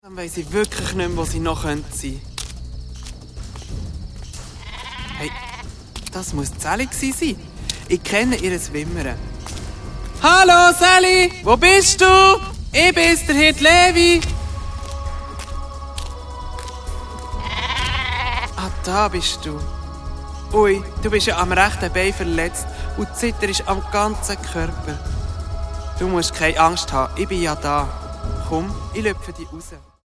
Hörspiel-Album